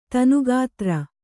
♪ tanu gātra